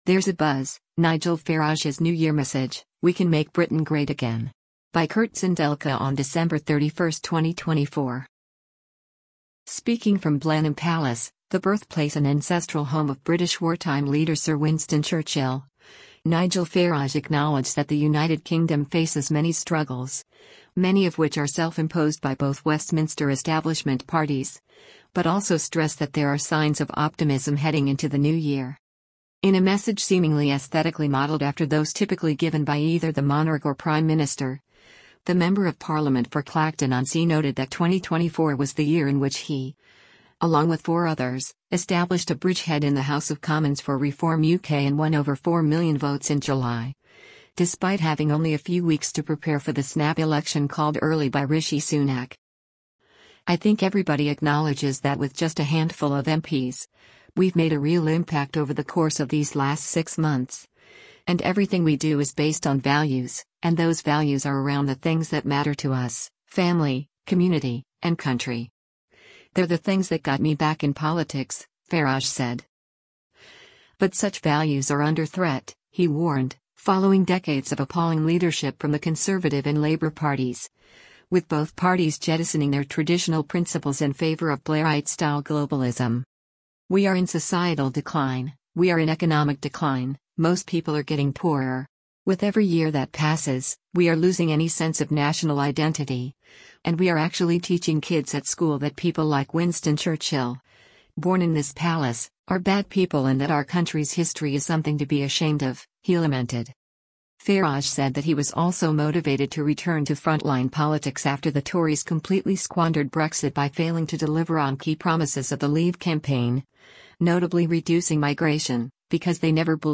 Speaking from Blenheim Palace, the birthplace and ancestral home of British wartime leader Sir Winston Churchill, Nigel Farage acknowledged that the United Kingdom faces many struggles, many of which are self-imposed by both Westminster establishment parties, but also stressed that there are signs of optimism heading into the new year.